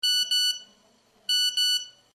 cell_ring_2.ogg